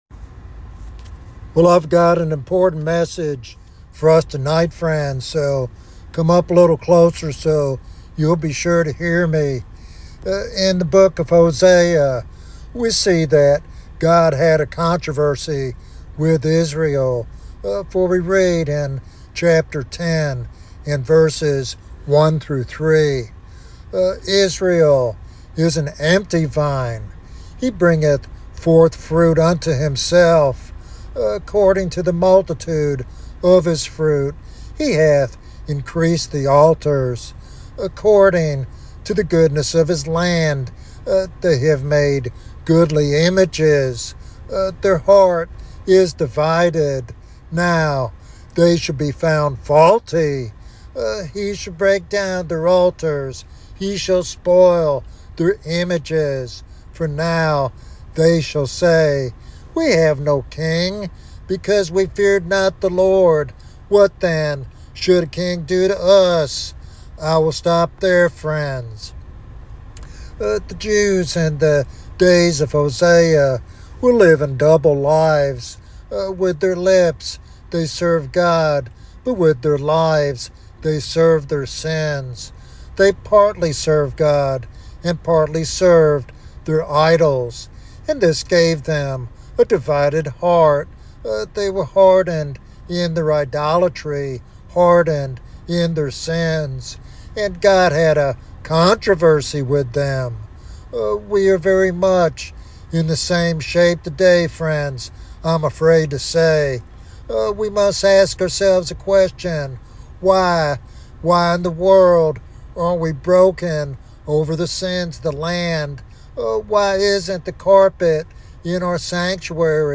In this powerful topical sermon titled "Brokenness